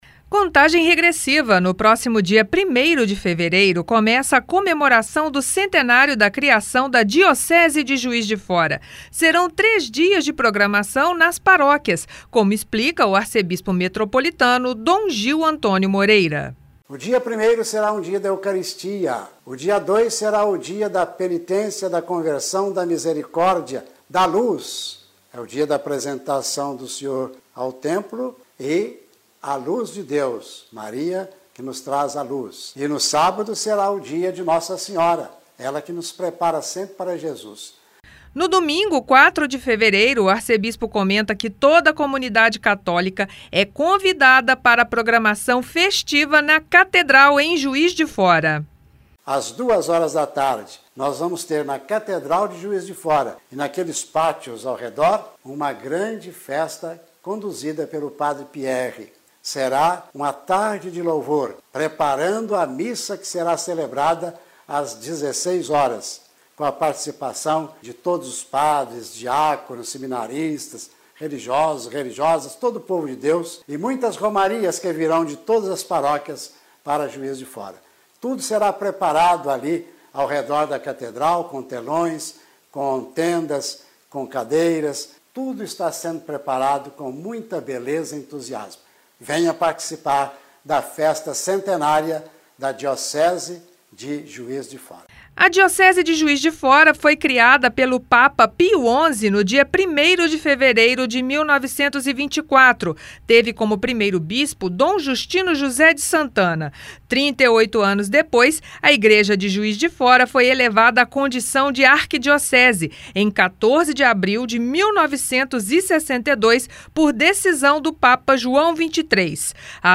O Arcebispo Metropolitano Dom Gil Antônio Moreira explica como serão os preparativos e as cerimônias previstas para o início do próximo mês. Ouça na reportagem.